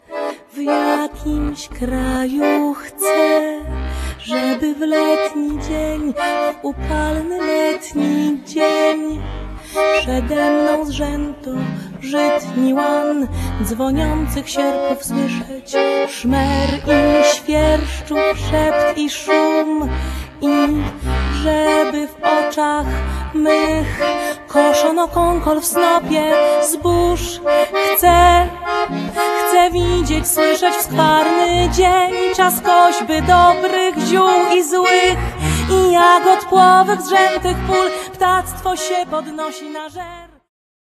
śpiew
akordeon